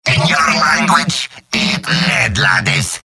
Robot-filtered lines from MvM. This is an audio clip from the game Team Fortress 2 .
Demoman_mvm_taunts15.mp3